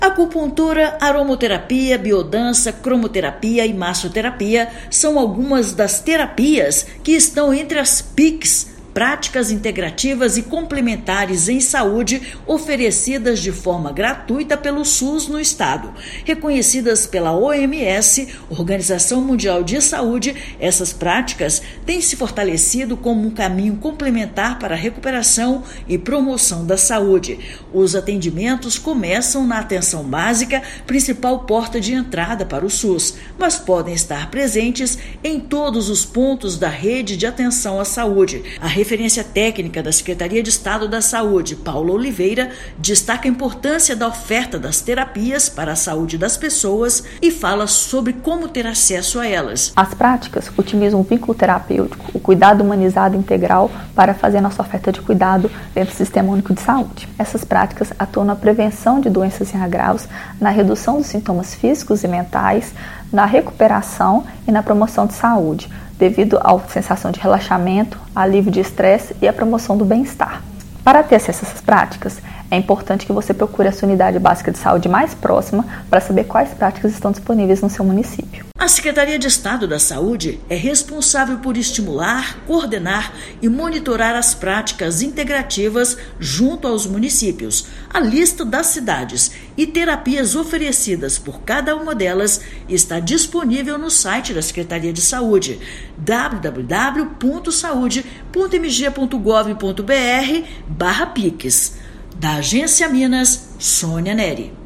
De acupuntura a yoga, atividades disponíveis no SUS auxiliam desde o tratamento de dores crônicas ao de ansiedade e depressão. Ouça matéria de rádio.